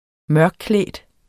Udtale [ ˈmɶɐ̯gˌklεˀd ]